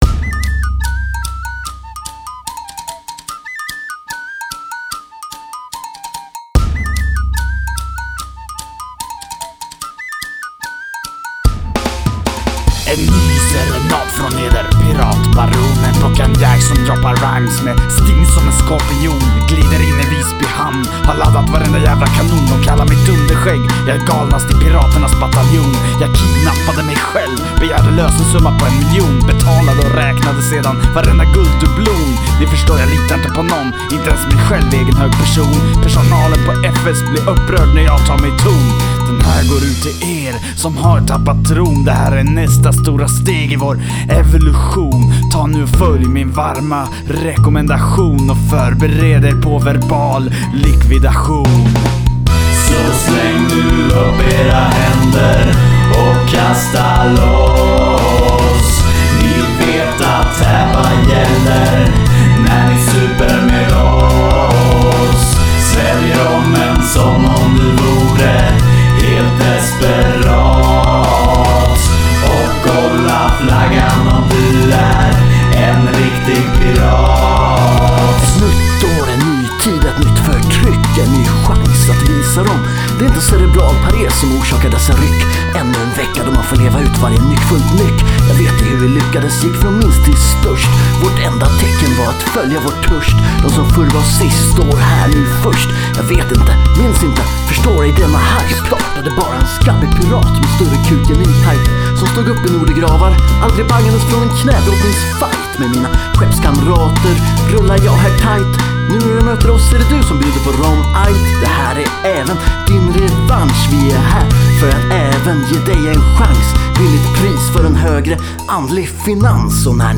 GenreHiphop